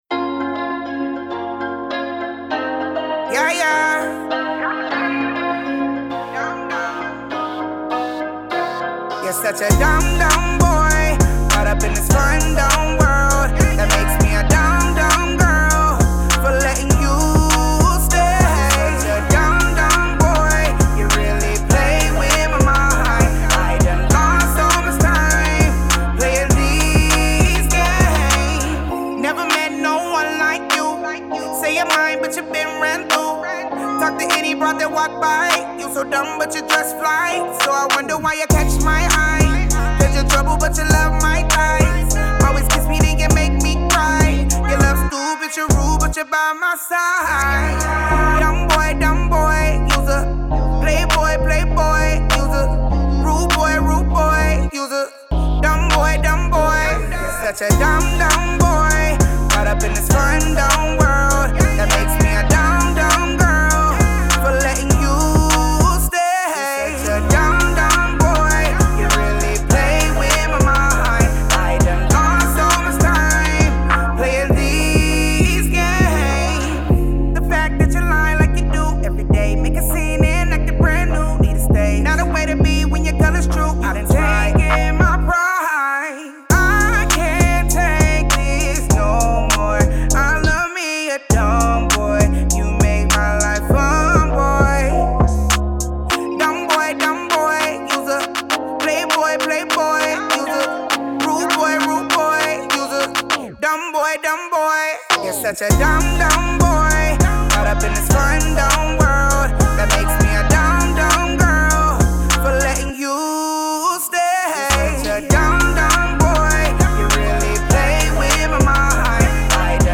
RnB
is the upbeat new single with urban and island influence